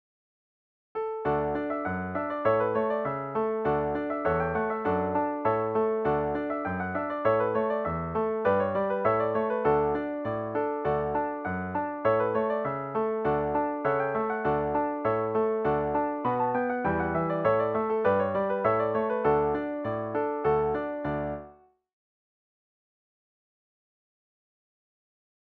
DIGITAL SHEET MUSIC - PIANO ACCORDION SOLO
Traditional Tunes, Scottish Hornpipe